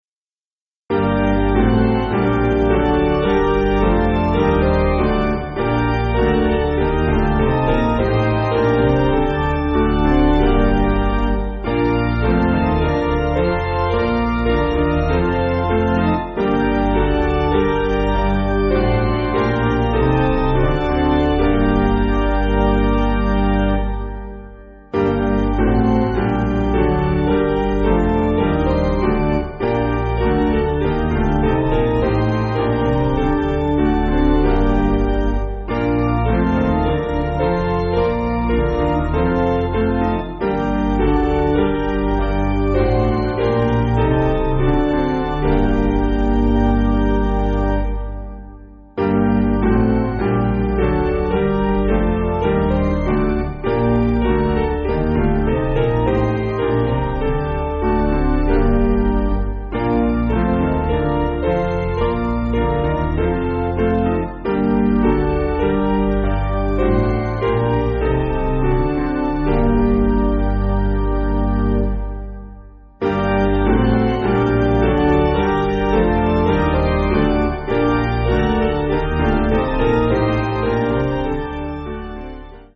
Basic Piano & Organ
(CM)   5/Gm